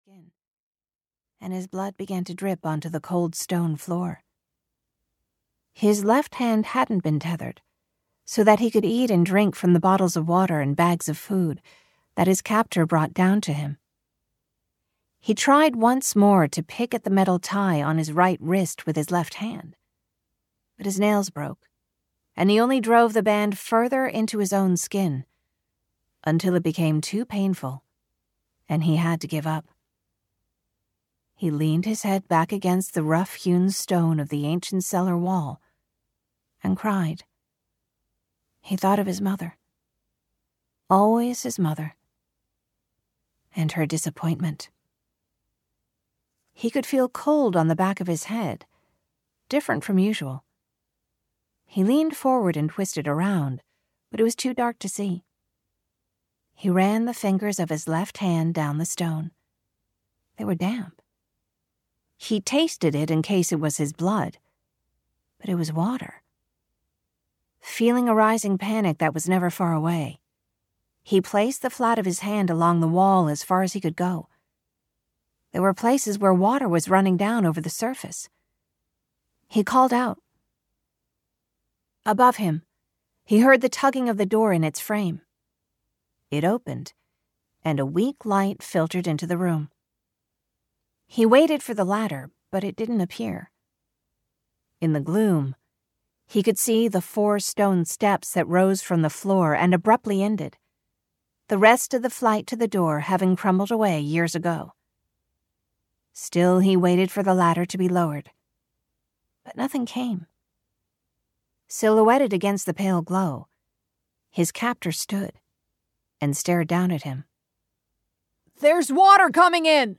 City of Drowned Souls (EN) audiokniha
Ukázka z knihy